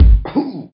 Talking Ben Poking Stomach